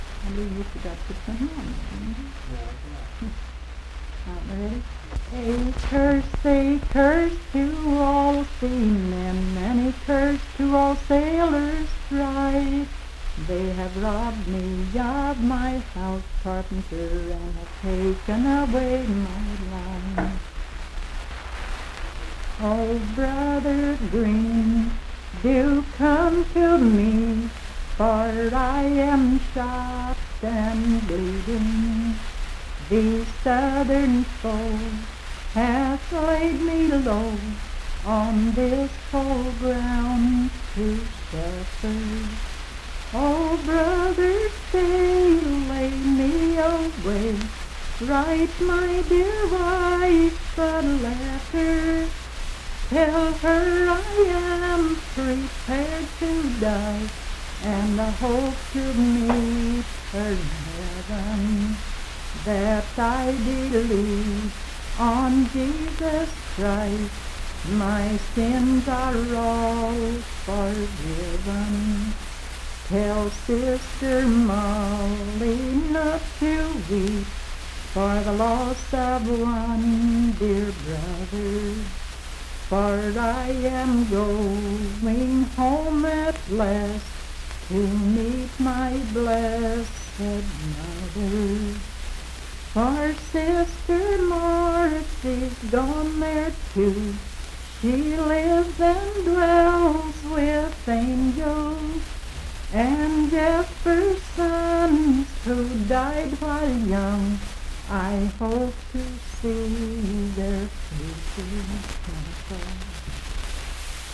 Unaccompanied vocal music
Laurel Dale, W.V..
Voice (sung)
Mineral County (W. Va.)